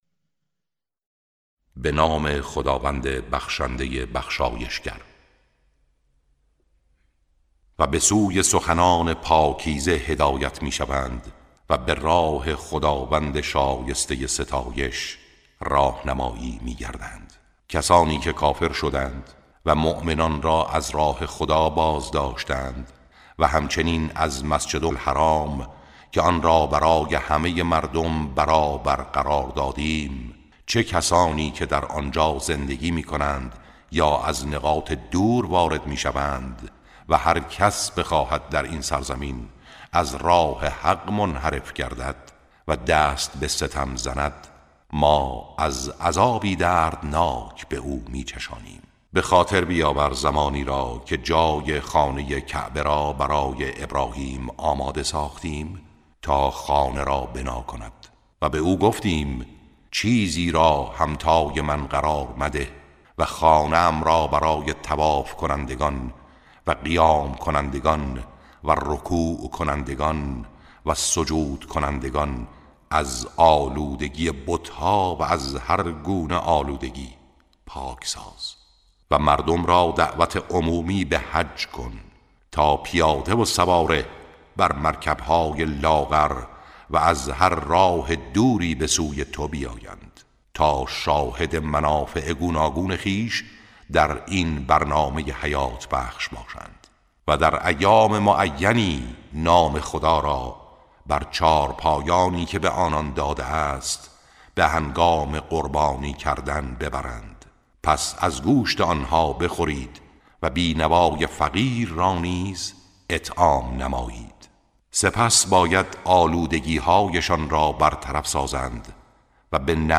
ترتیل صفحه ۳۳۵ سوره مبارکه حج(جزء هفدهم)